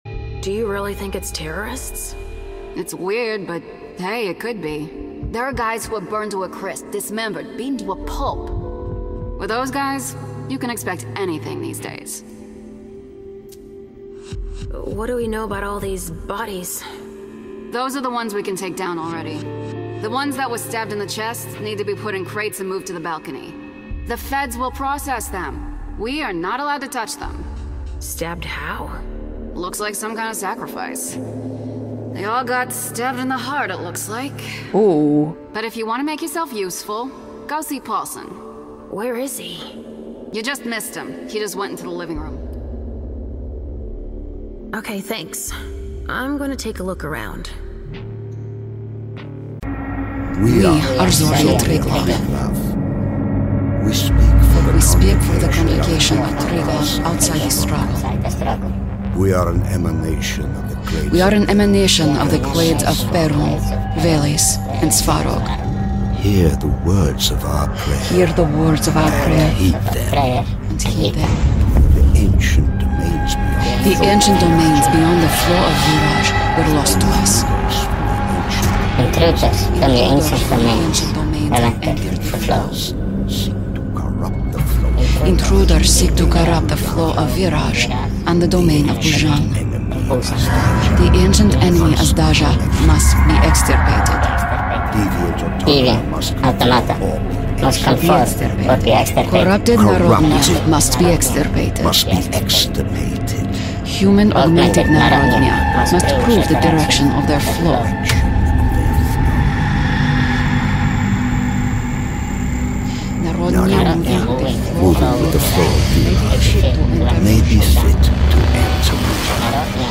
From witty and wry to warm and compassionate, I've got you.
Video Game Reel